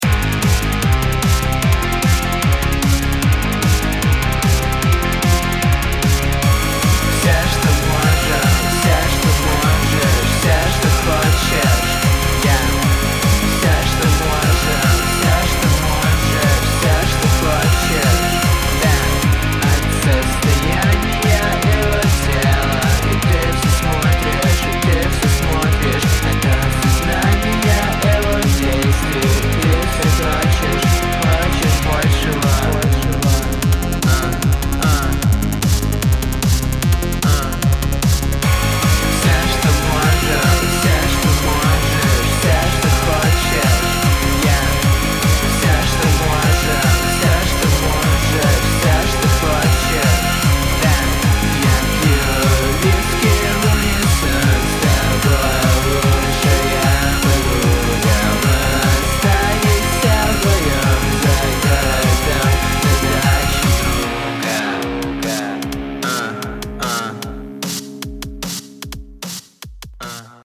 Драмсы вытягивайте Типа так?:D